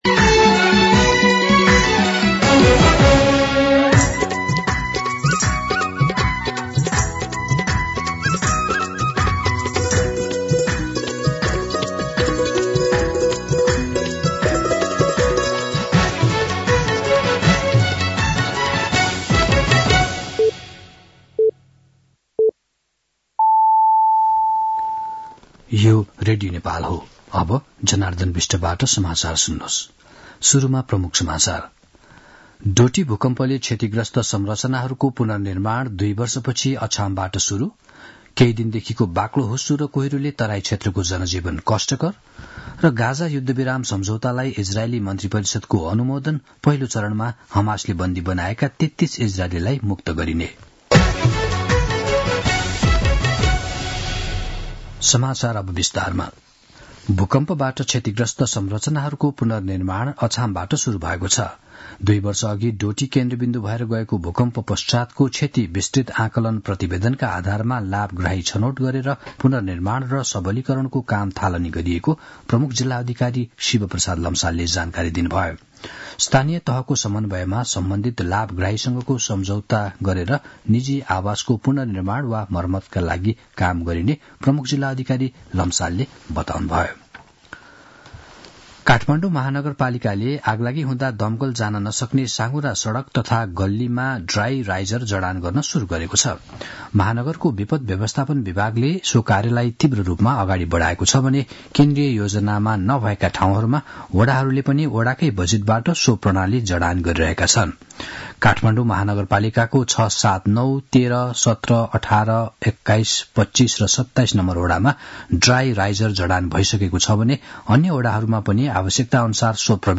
दिउँसो ३ बजेको नेपाली समाचार : ६ माघ , २०८१
3-pm-Nepali-News-1.mp3